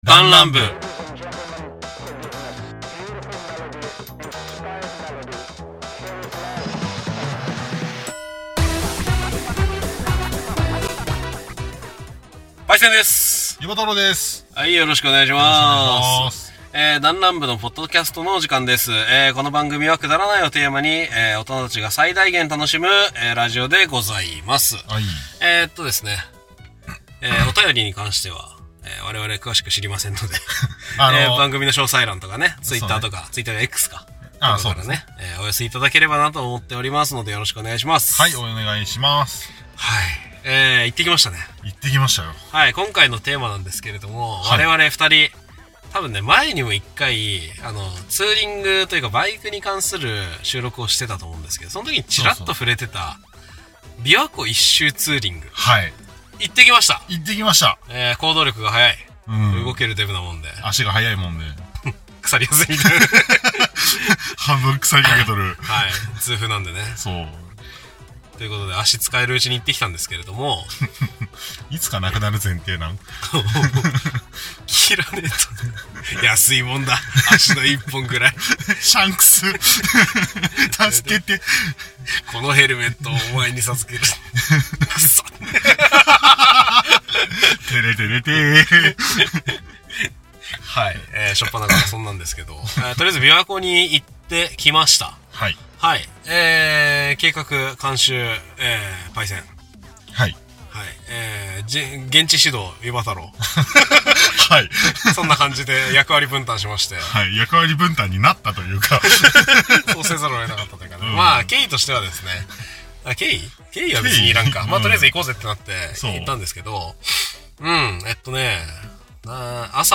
だんらん部 -アラサー男達の勝手なる雑談会-
楽しむことを妥協しない社会人 7人組 のお話。